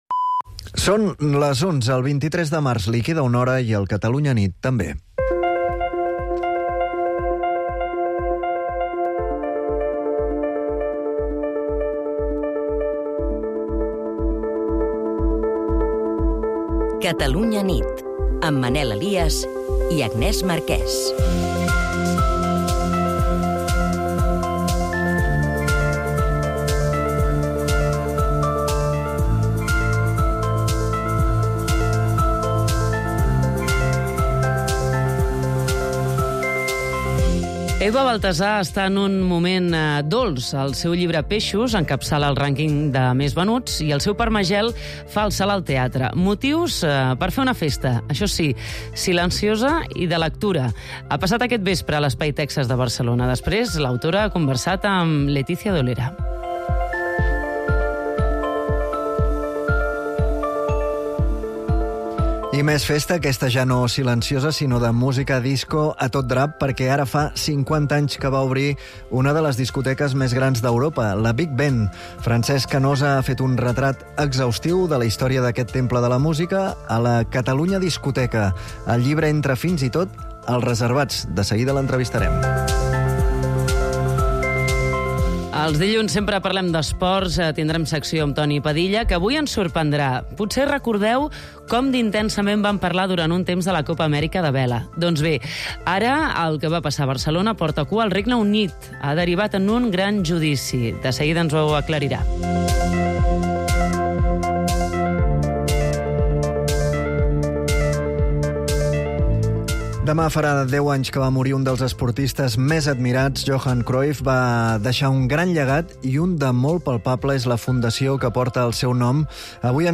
l'informatiu nocturn de Catalunya Ràdio